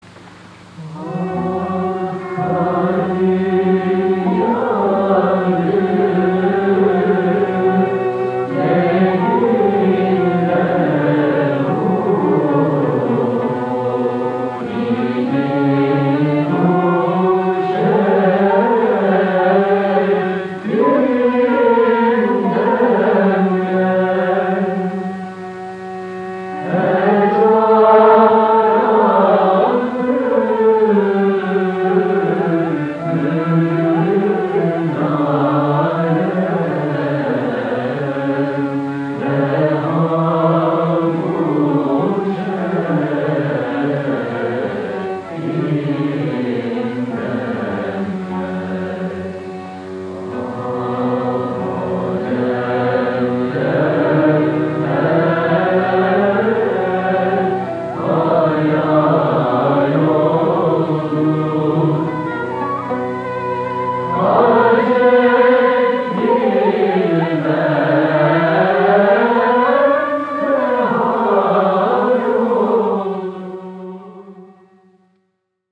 Genre: Turkish & Ottoman Classical.
Classical Turkish Music Chorus
Directed by Mesut Cemil (cello) recorded on 7/23/1963. Announcer: Mesut Cemil   3:06